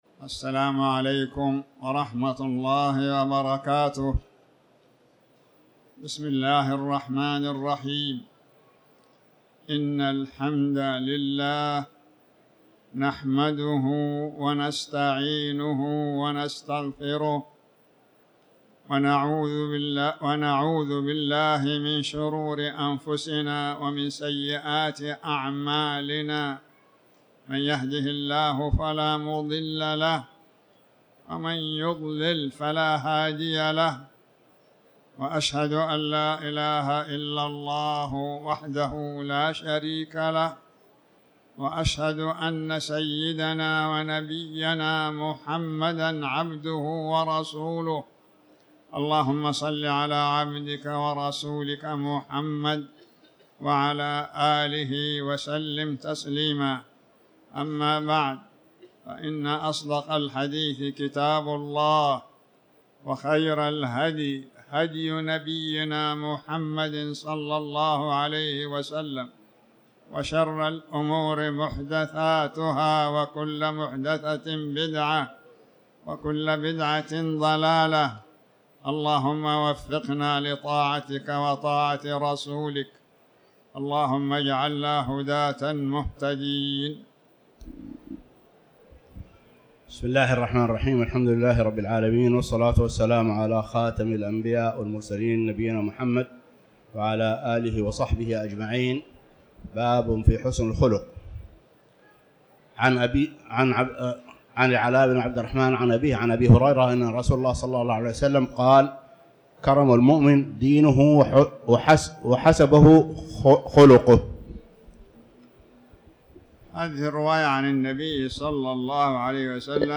تاريخ النشر ٤ شعبان ١٤٤٠ هـ المكان: المسجد الحرام الشيخ